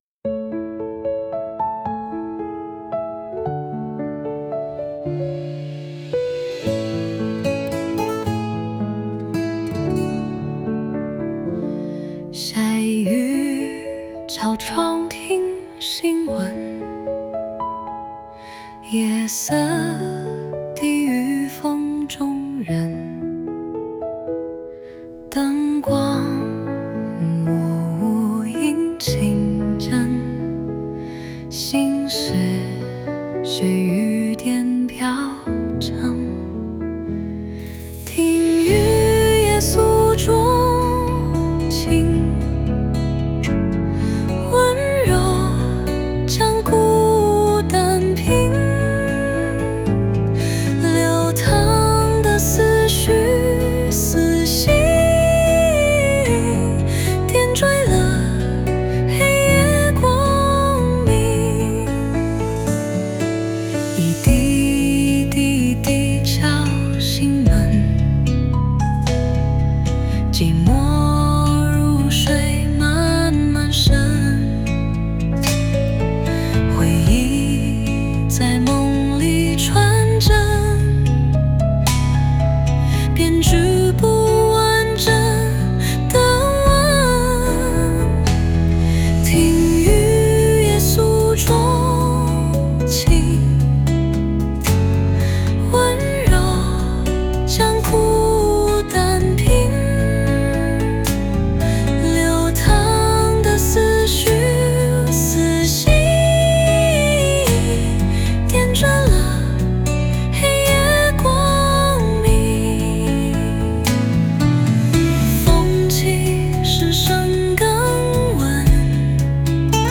Ps：在线试听为压缩音质节选，体验无损音质请下载完整版 无歌词